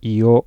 日本語の連続２母音の波形データの例。
io_1.wav